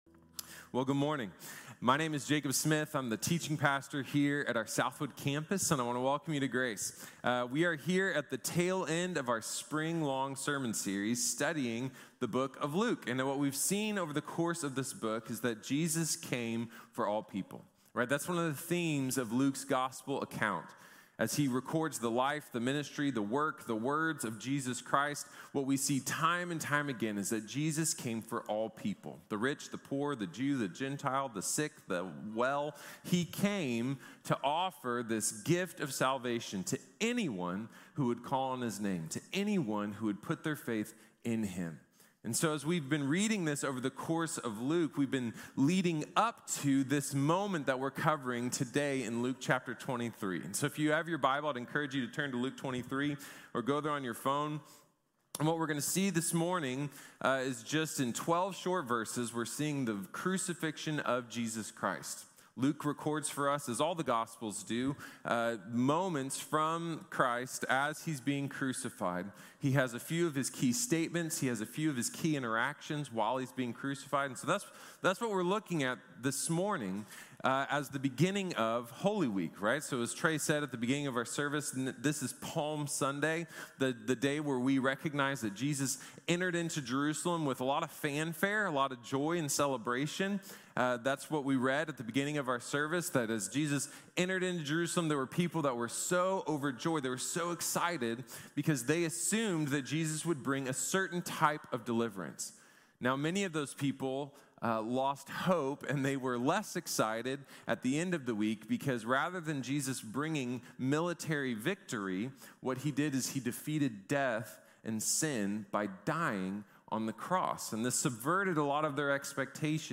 The Crucifixion | Sermon | Grace Bible Church